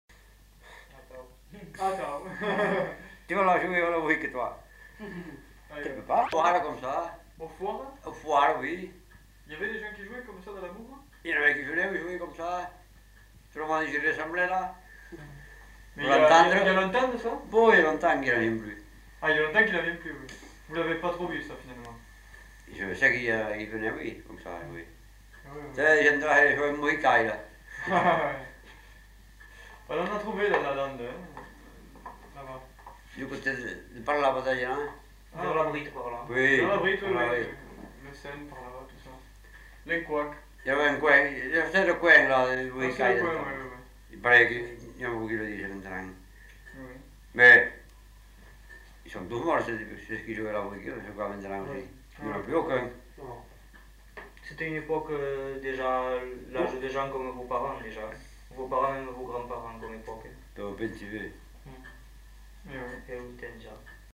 Aire culturelle : Gabardan
Lieu : Herré
Genre : témoignage thématique